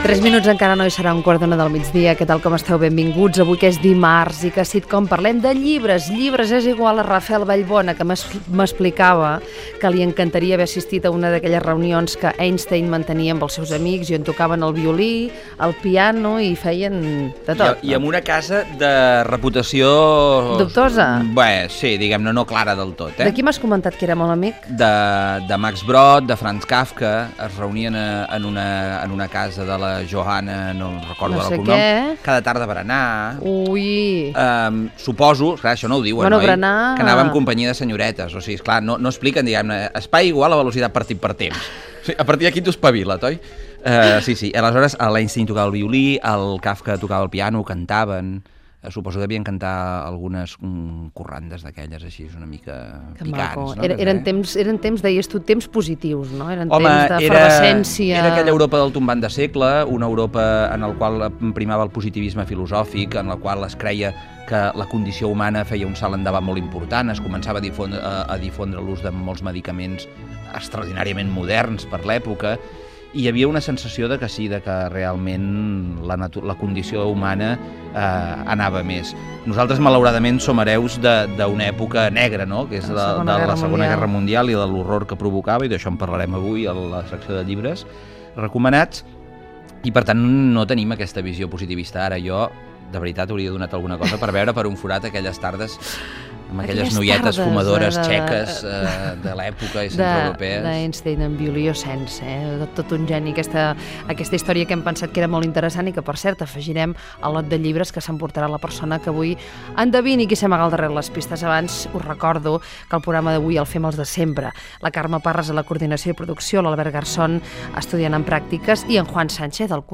Entreteniment
FM
Fragment extret de l'arxiu sonor de COM Ràdio.